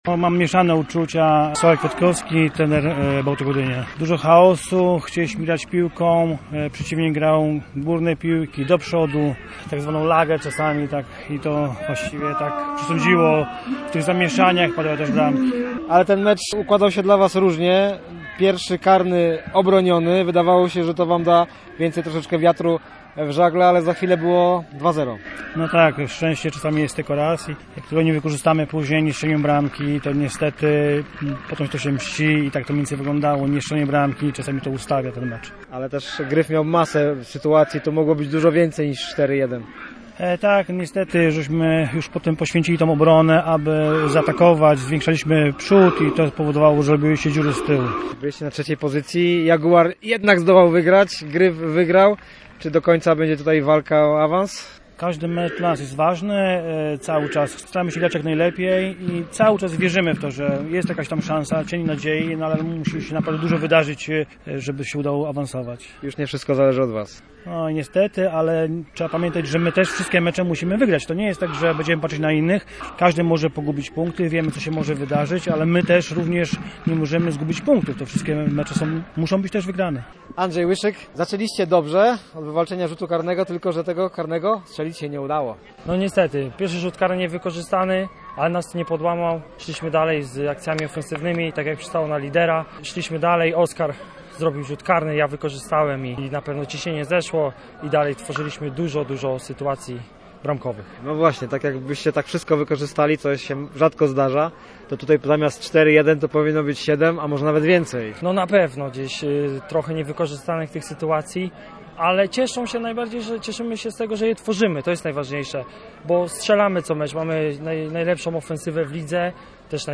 Posłuchaj pomeczowych wypowiedzi: https